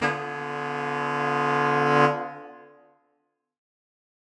Index of /musicradar/undercover-samples/Horn Swells/D
UC_HornSwell_Ddim.wav